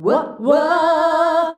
UAH-UAAH E.wav